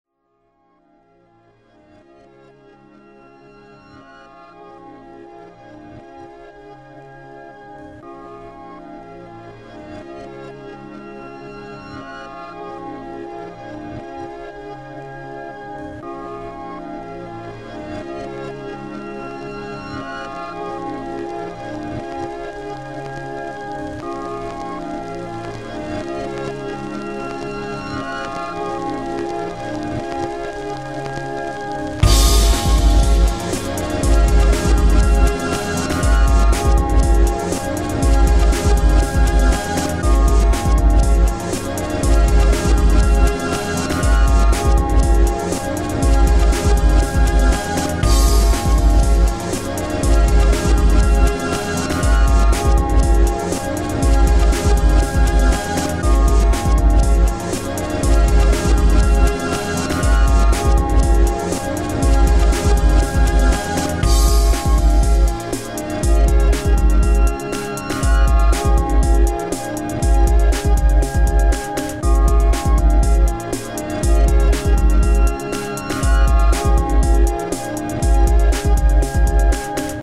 Guitares, samples, claviers, programmations, bricolages.
Saxophone soprano
à évolué vers un son plus électronique